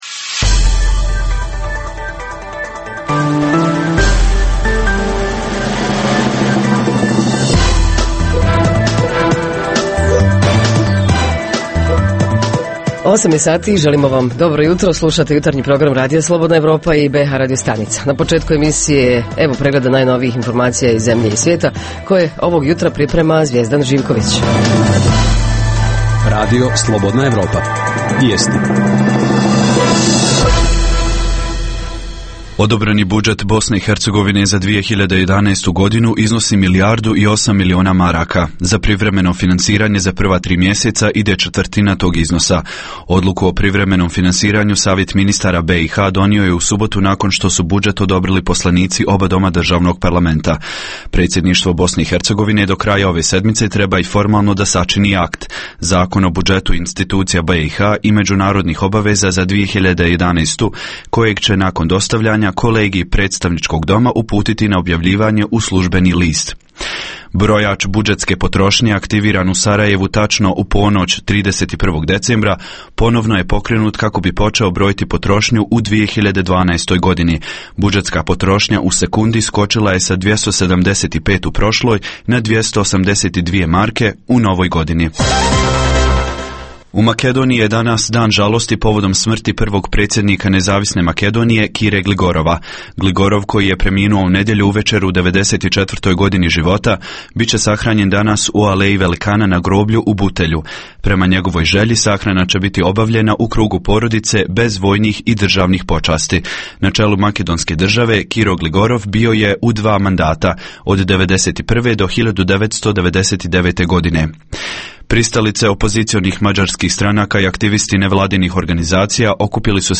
Jutarnji program za BiH koji se emituje uživo. U prazničnom raspoloženju, govorimo o najzanimljivijim događajima u vašem gradu, kantonu, entitetu.
Redovni sadržaji jutarnjeg programa za BiH su i vijesti i muzika.